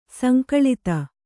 ♪ sankaḷita